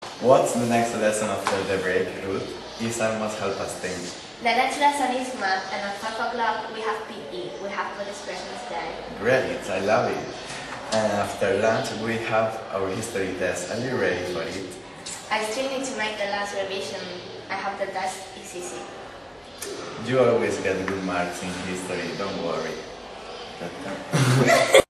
Everyday conversations
Chico y chica de pié en pasillo de instituto mantienen una conversación